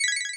Retro Game Weapons Sound Effects – Key – Free Music Download For Creators
Retro_Game_Weapons_Sound_Effects_-_key.mp3